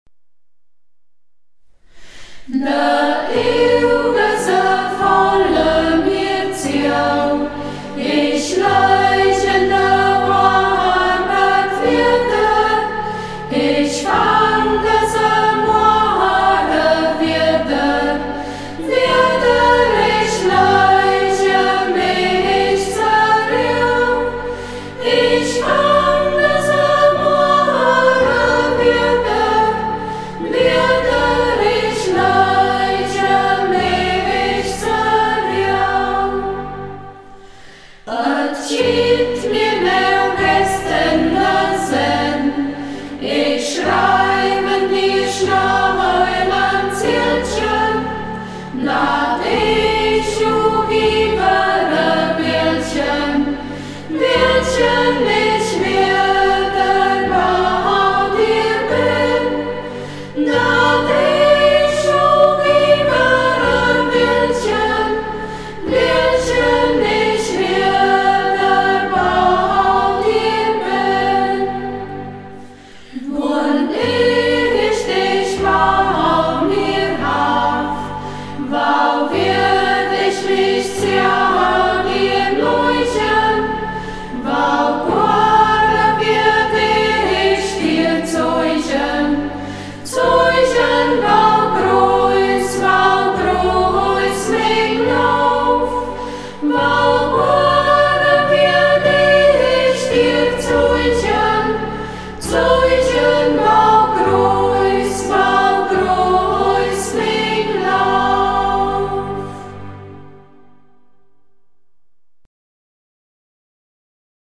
Ortsmundart: Burgberg